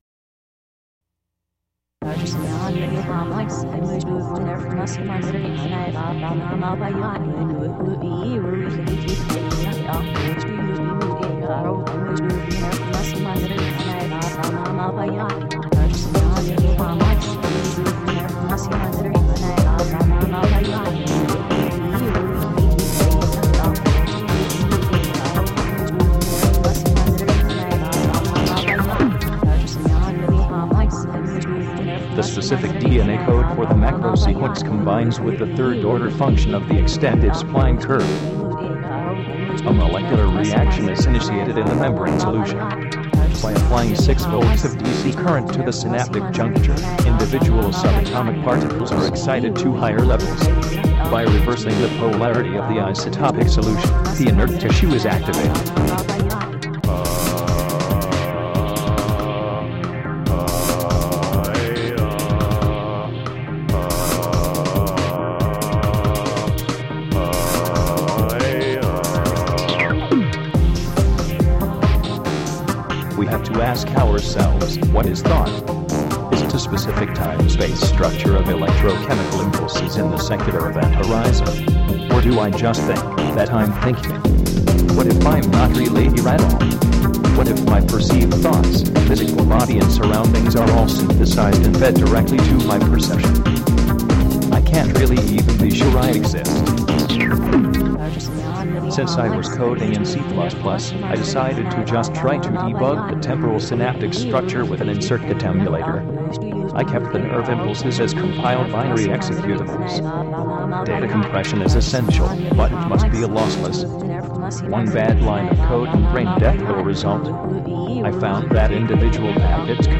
The world's first virtual band.
Awesomely nerdy new wave here folks.
fun, driving techno
It's also fantastic workout music.
Tagged as: Electronica, Techno